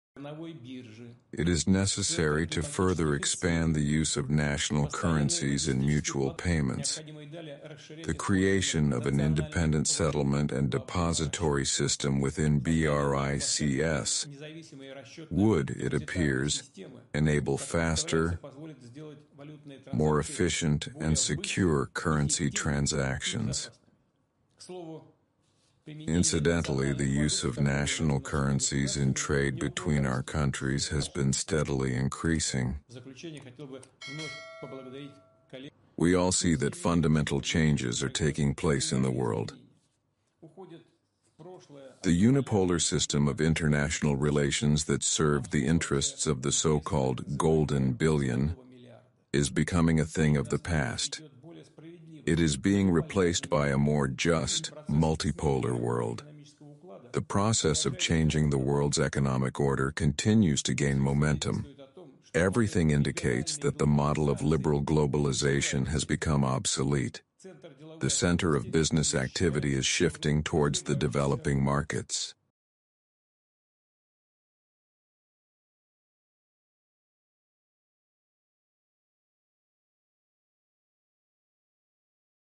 Putin English Speech At BRICS sound effects free download